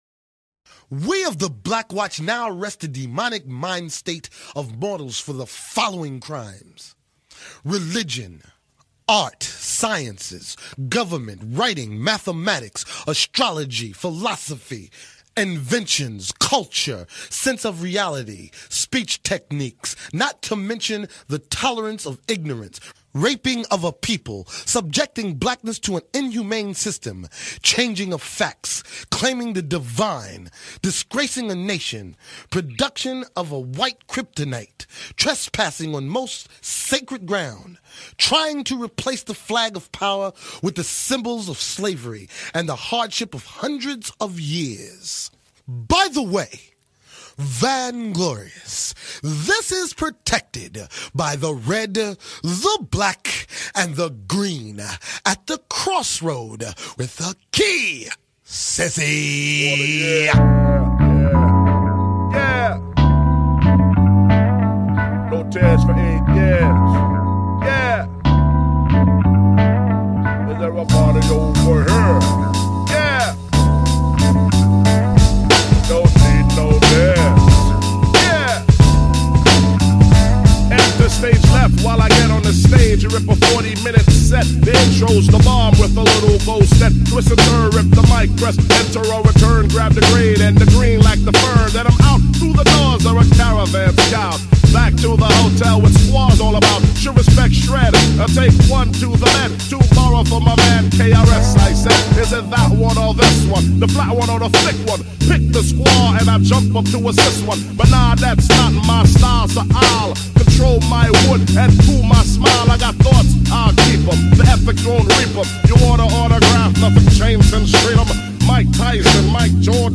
Beats Hip Hop